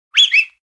whistle1.ogg